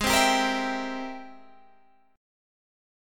Ab+M9 chord